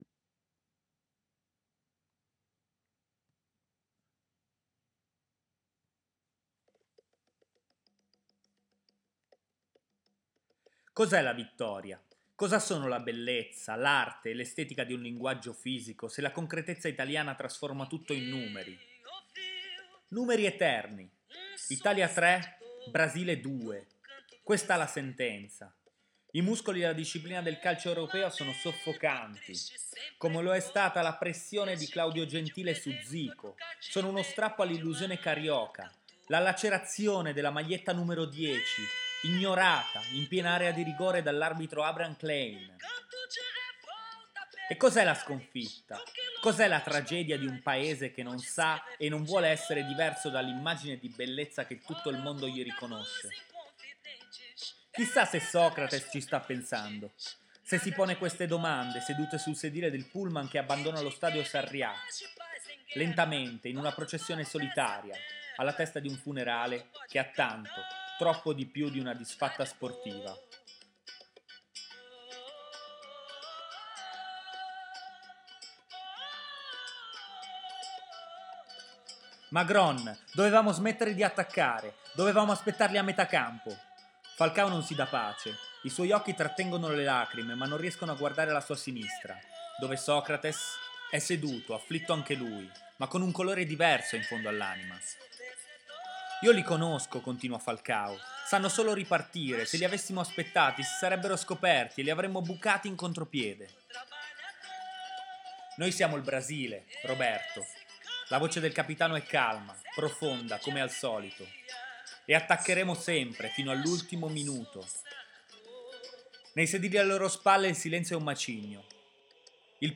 Musica e parole in sottofondo: Clara Nunes, Canto das três raças